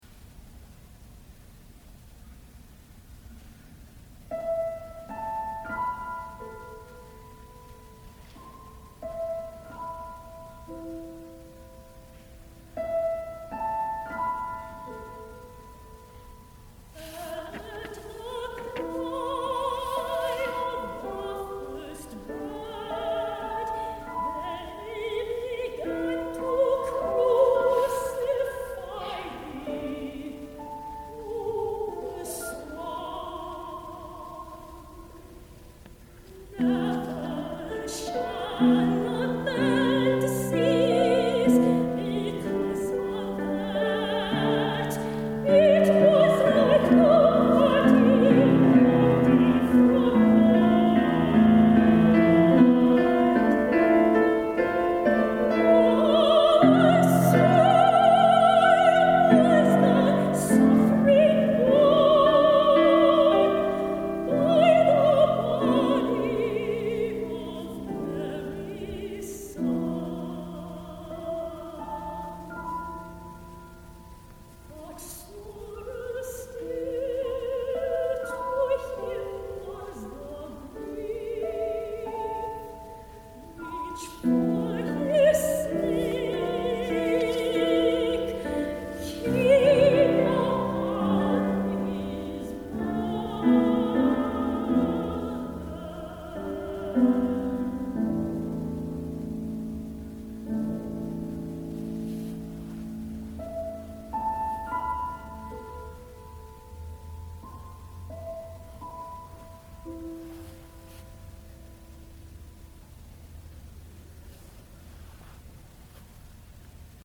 piano
*live performances